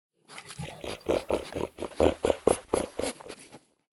Minecraft Version Minecraft Version latest Latest Release | Latest Snapshot latest / assets / minecraft / sounds / mob / wolf / grumpy / panting.ogg Compare With Compare With Latest Release | Latest Snapshot
panting.ogg